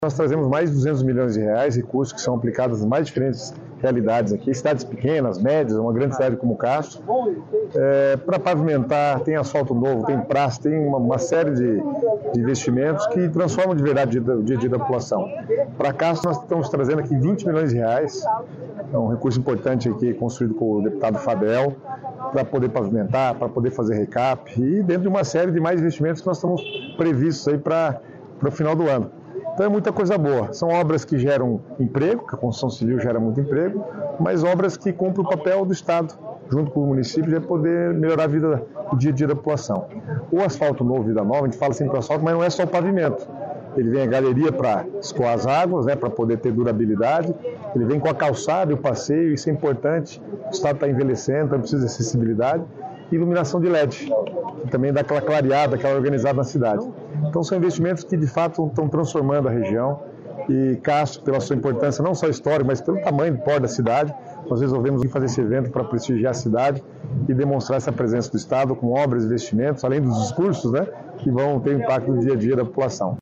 Sonora do secretário de Estado das Cidades, Guto Silva, sobre os investimentos nos Campos Gerais